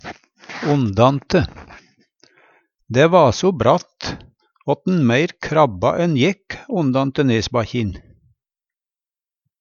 Tilleggsopplysningar Ondate blir også sagt